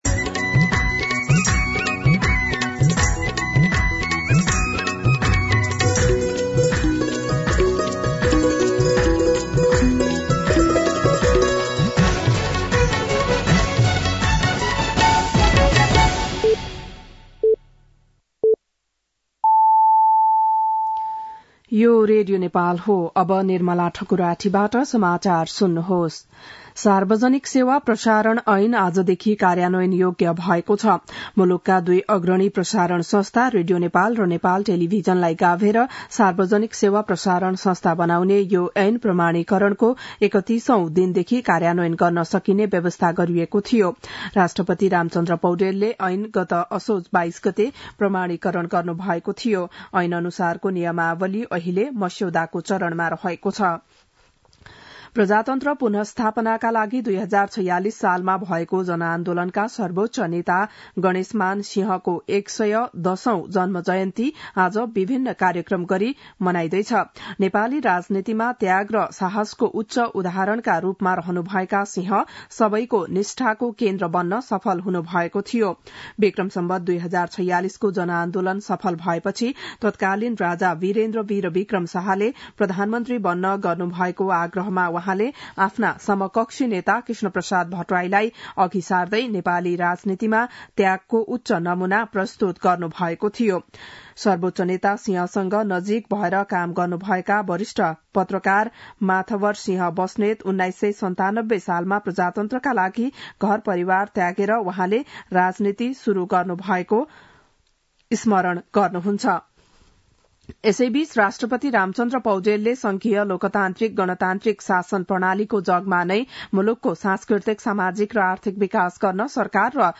बिहान ११ बजेको नेपाली समाचार : २५ कार्तिक , २०८१
11-am-Nepali-News-2.mp3